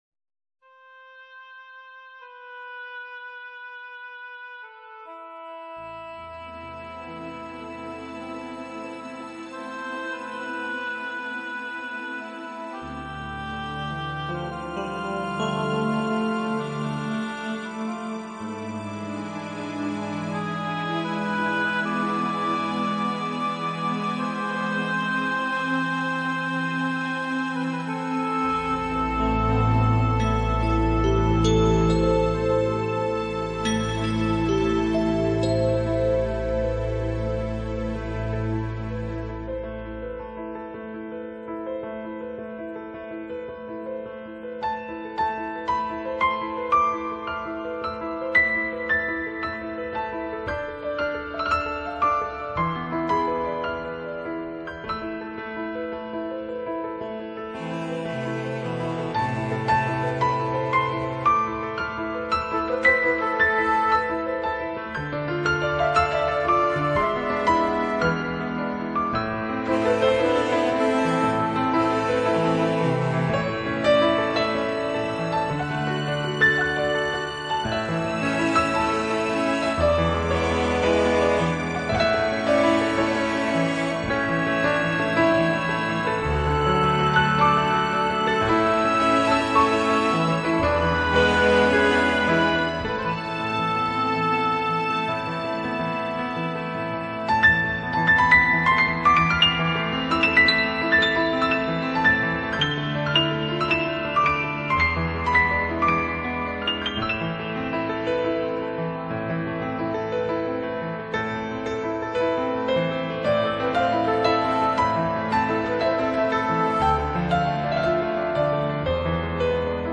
轻柔的吉它、优雅的长笛佐以婉约的钢琴